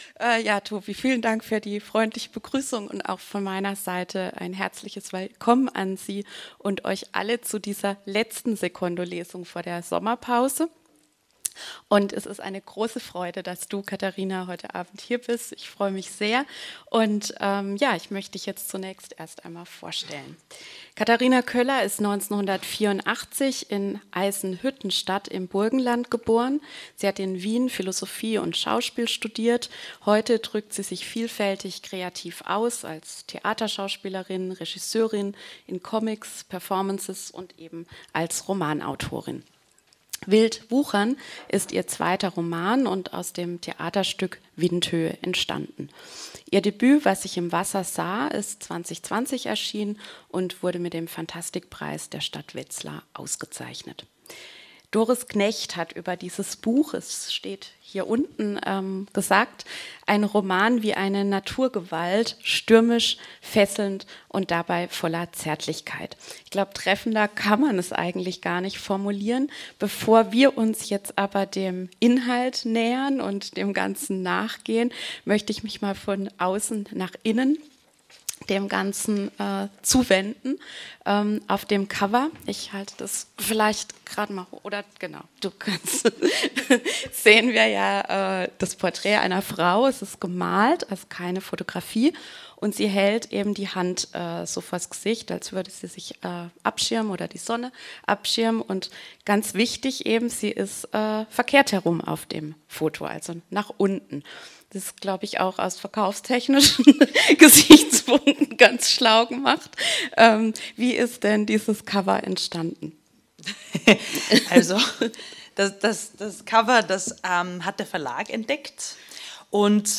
Lesung
Audio-Mitschnitt der Veranstaltung (Dauer: 1:14 h): MP3 in neuem Fenster öffnen | Download MP3 (79 MB)
Ermäßigt EUR 2,50 Lesung und Gespräch in der Reihe Secondo Moderation